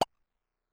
New grunk collection SFX